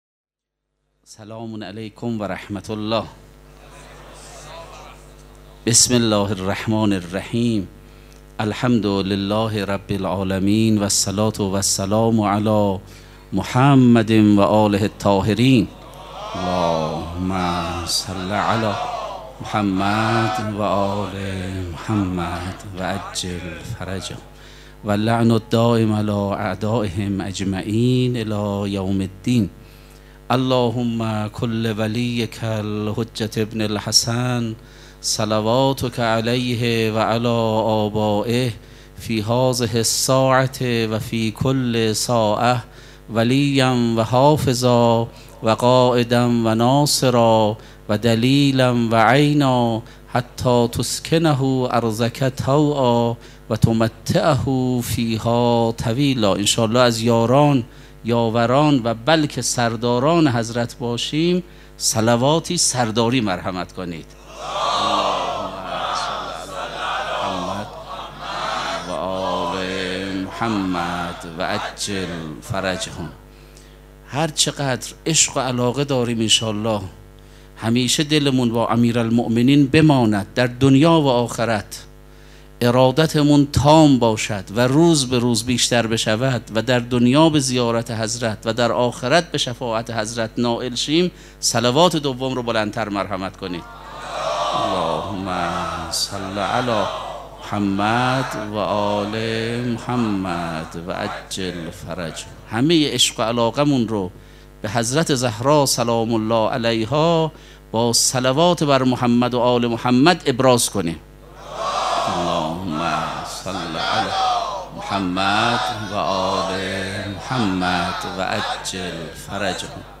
سخنرانی
دانلود تصویر سخنرانی favorite شنبه ۳ اردیبهشت ۱۴۰۱ | ۲۱ رمضان ۱۴۴۳ حسینیه ریحانة‌الحسین (سلام‌الله‌علیها)